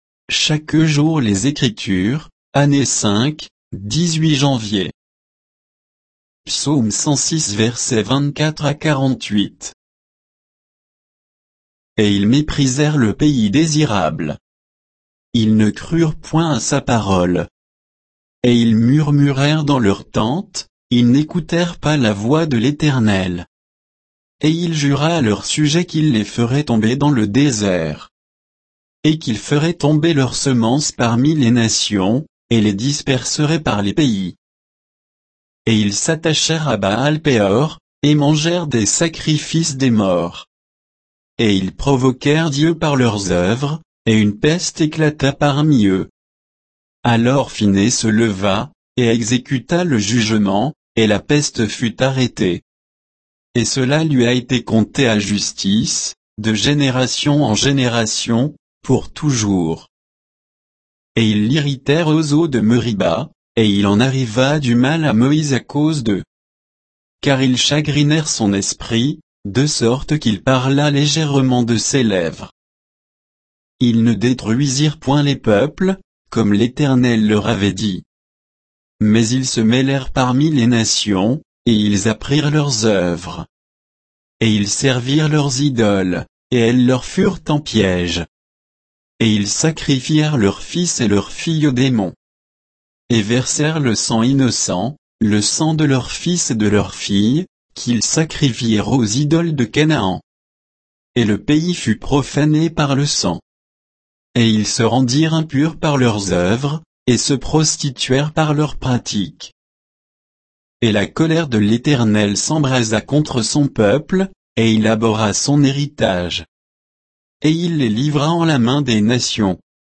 Méditation quoditienne de Chaque jour les Écritures sur Psaume 106